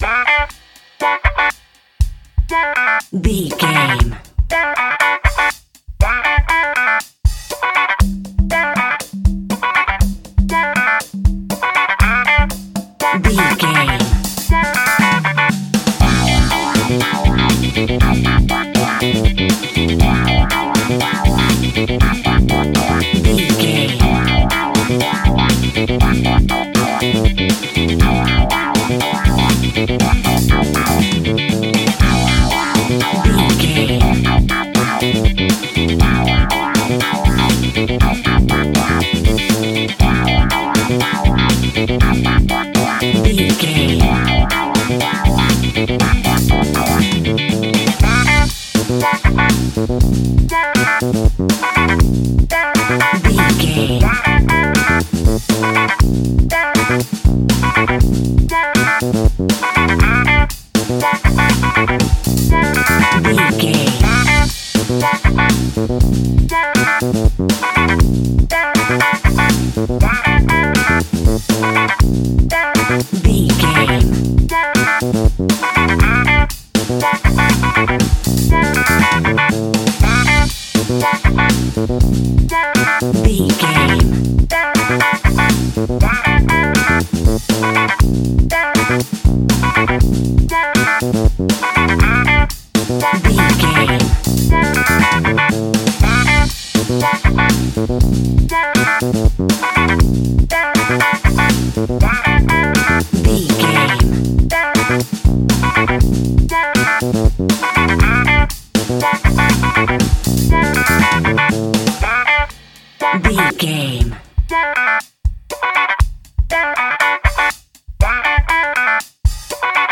Aeolian/Minor
groovy
uplifting
bouncy
smooth
drums
electric guitar
bass guitar
funky house
disco house
electro funk
upbeat
synth leads
Synth pads
synth bass
drum machines